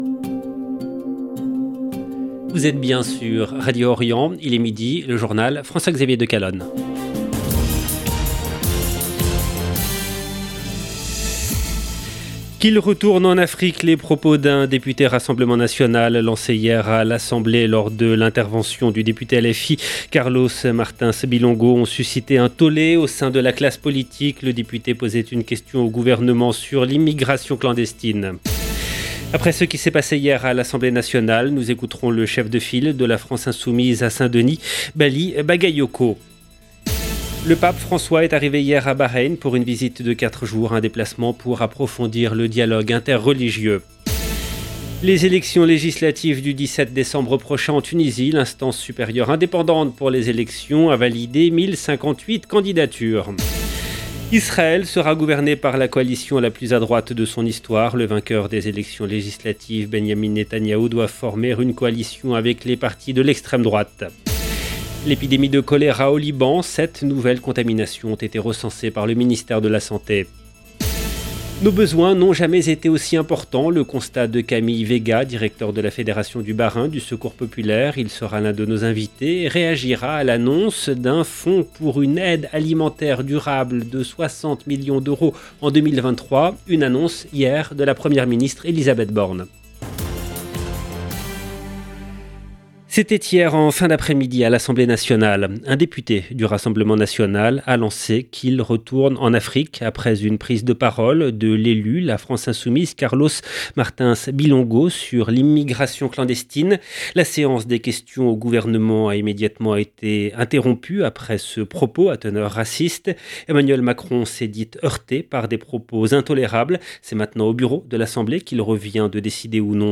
EDITION DU JOURNAL EN LANGUE FRANCAISE DU 4/11/2022